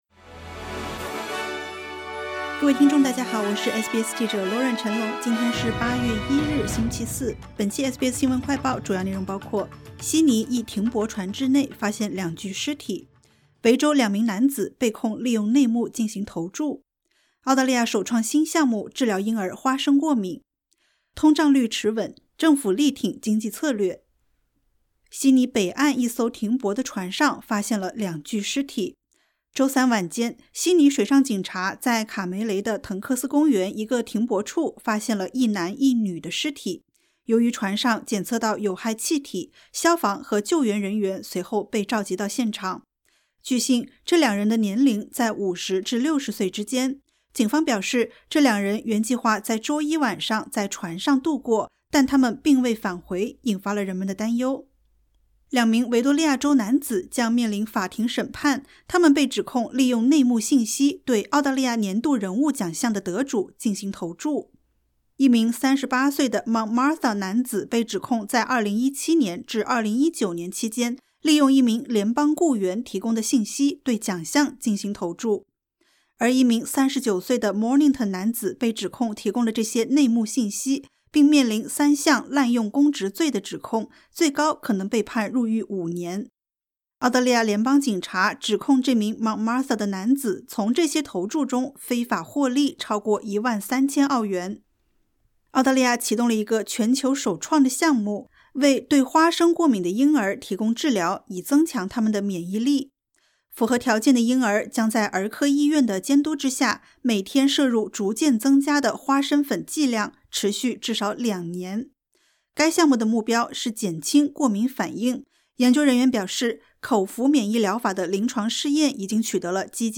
【SBS新闻快报】澳大利亚首创新项目治疗婴儿花生过敏